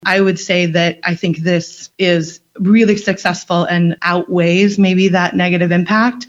One business owner spoke in favor of the resolution